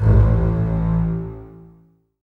strTTE65003string-A.wav